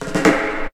18DR.BREAK.wav